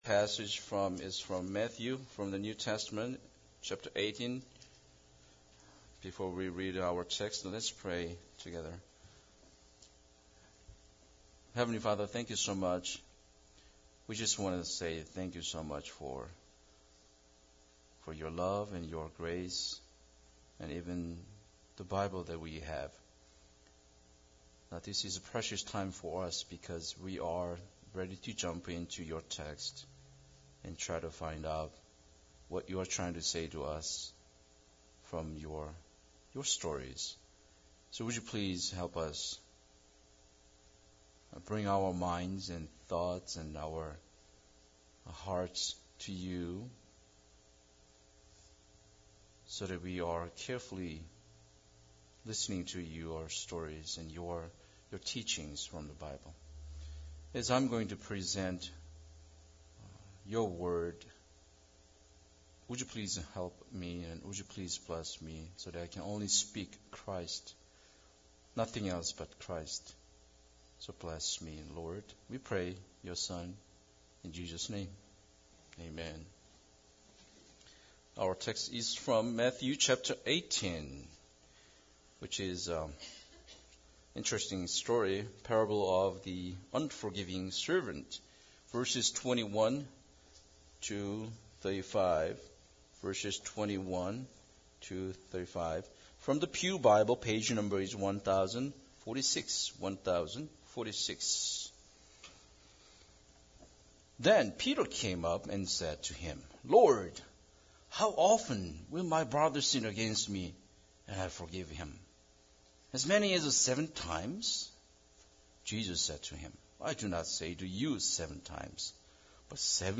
Matthew18:21-35 Service Type: Sunday Service Bible Text